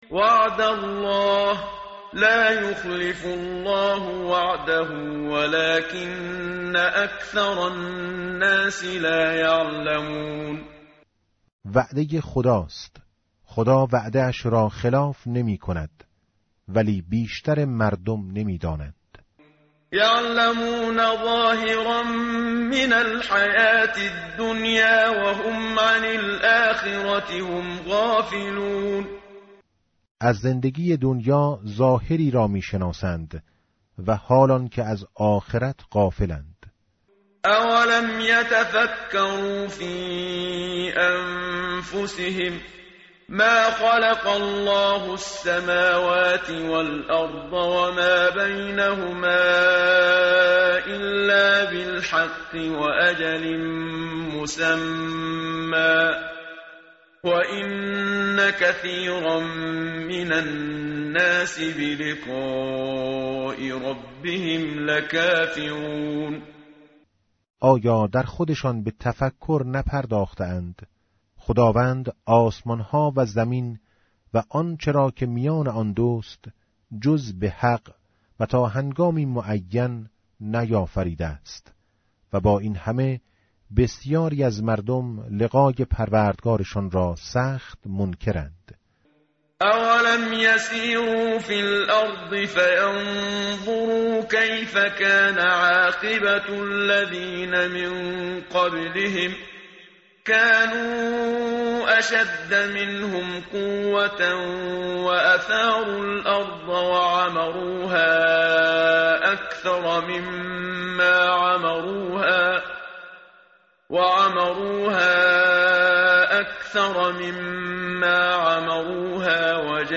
tartil_menshavi va tarjome_Page_405.mp3